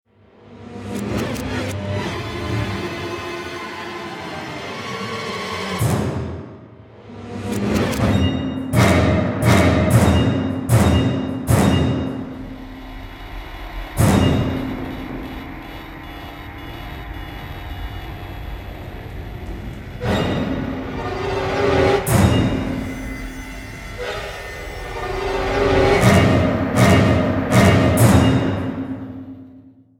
Здесь вы найдете жуткие стоны, таинственные шорохи, зловещий смех и другие эффекты, которые сделают ваш праздник по-настоящему пугающим.
Звуки психбольницы, где прячутся твои страхи